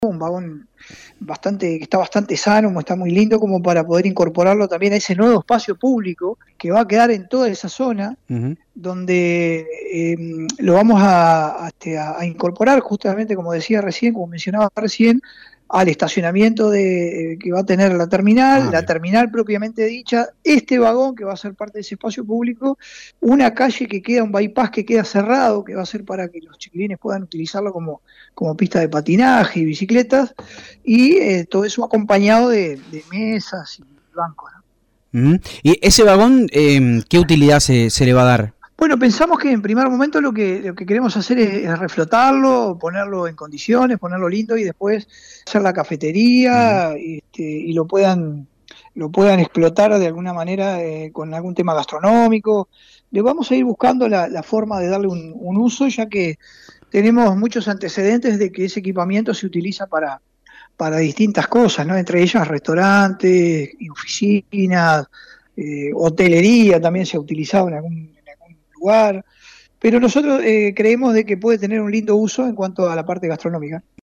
Escuchamos al alcalde de Ecilda Paullier Leonardo Giménez…